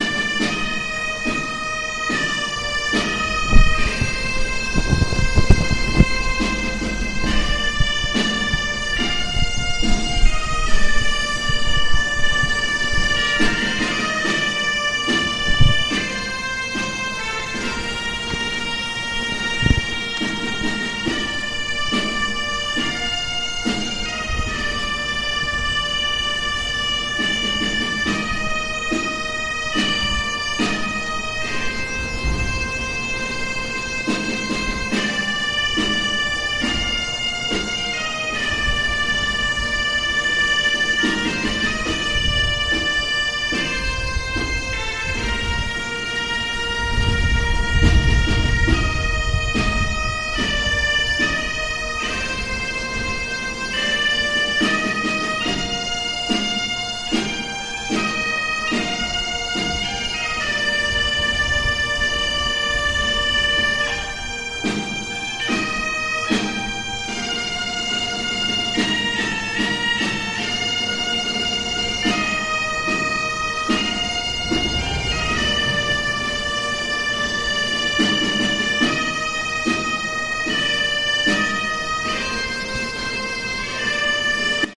La Real Banda de Gaitas Ciudad de Oviedo interpreta "Antón el Neñu"
Durante esos tres minutos sonaba la “Marcha d'Antón el Neñu”, una marcha fúnebre cuyo origen se sitúa en el siglo XIX. Tres minutos de congoja que finalizaba con un sonoro aplauso.